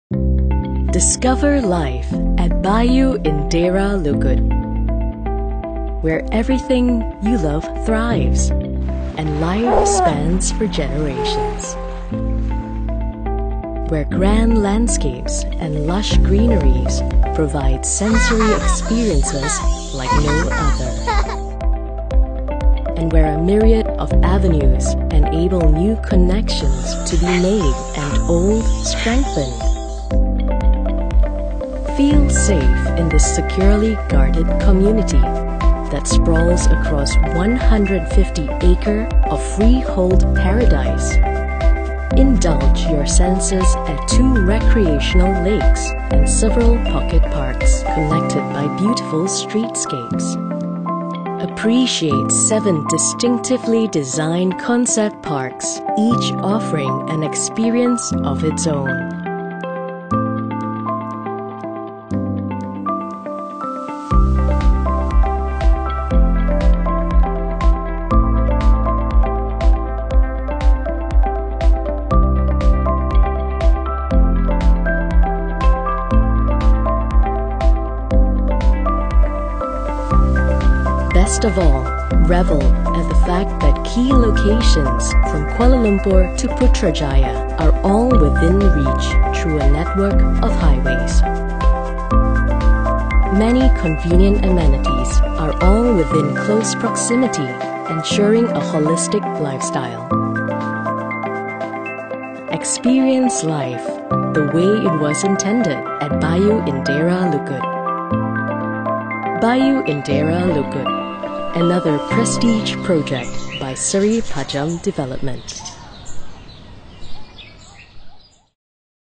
Female
_Voice Over sample recorded using my own audio equipment Voice Over sample recorded using my own audio equipment
Calm, Gentle, Warm - Announcer (Property - Bayu Indera Lukut, Seri Pajam Development) Announcer VO for Bayu Indera Lukut, Seri Pajam Development